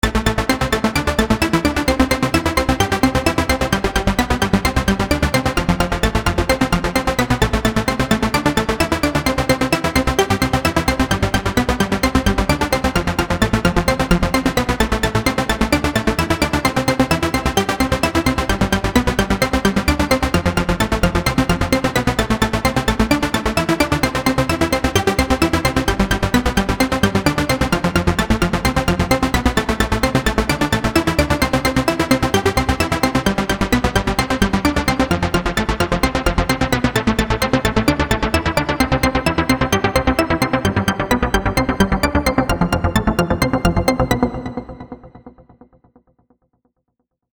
• Качество: 320, Stereo
громкие
remix
веселые
Electronic
без слов